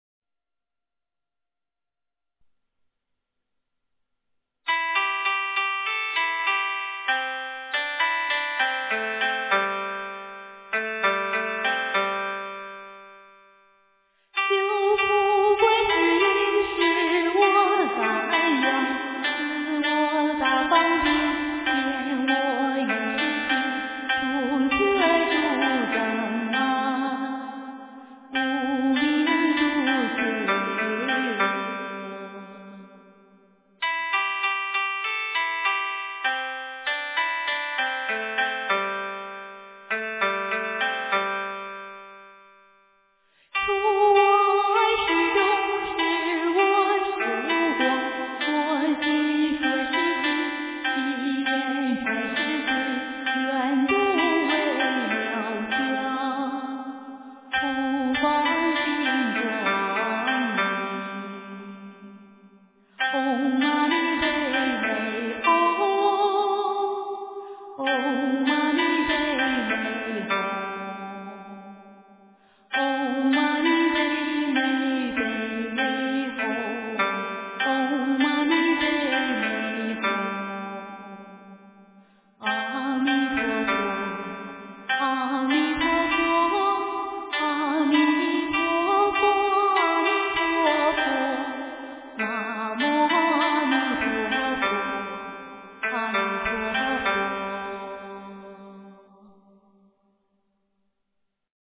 观音菩萨洗眼咒--佛教音乐